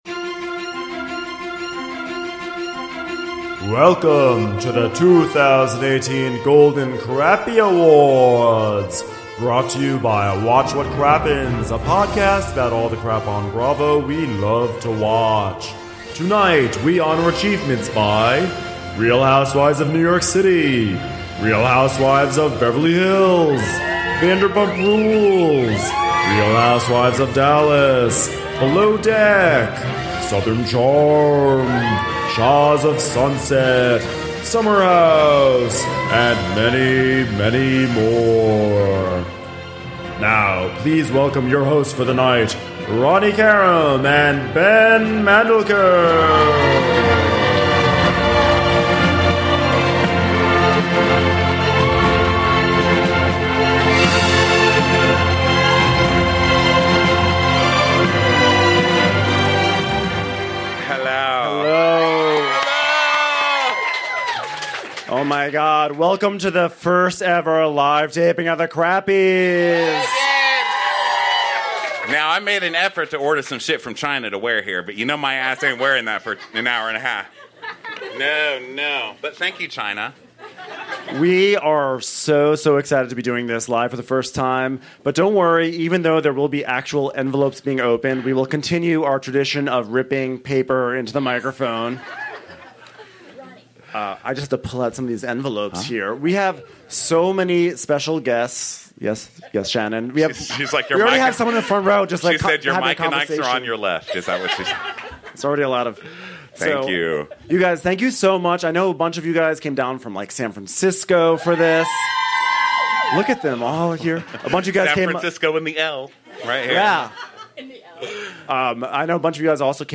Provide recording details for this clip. It's time for the annual Golden Crappy Awards where we honor achievement in all things Bravo. For the first time ever, we've upped the stakes, recording in front of a live audience at The Hollywood Improv! Plus, stick around for a very special audience moment!!